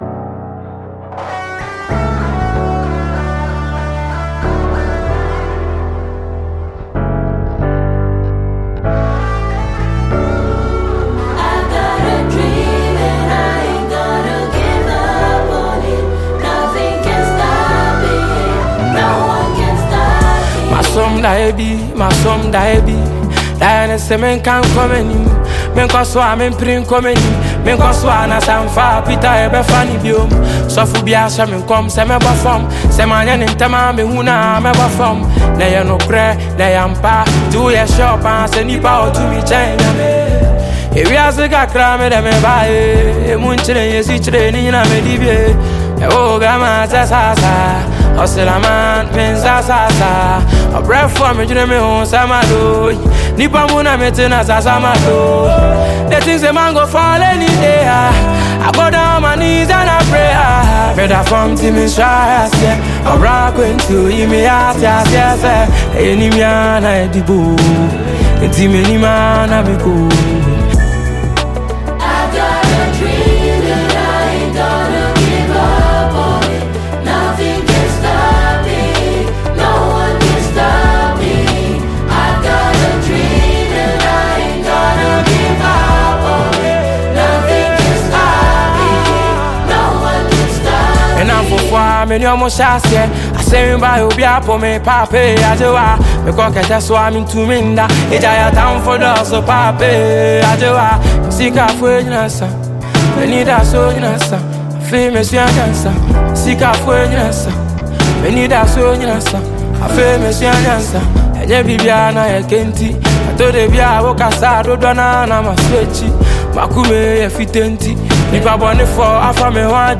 Talented Ghanaian singer and songwriter